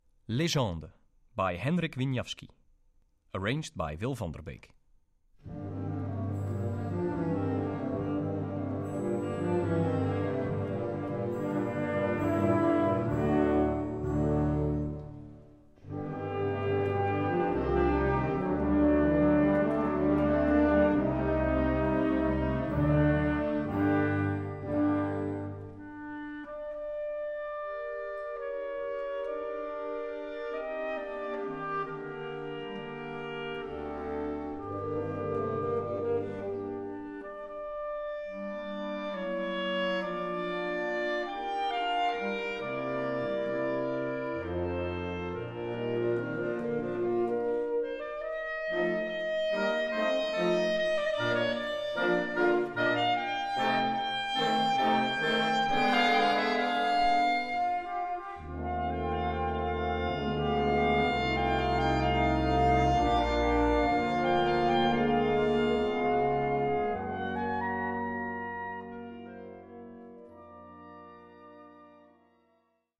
Gattung: Solo für Klarinette und Blasorchester
Besetzung: Blasorchester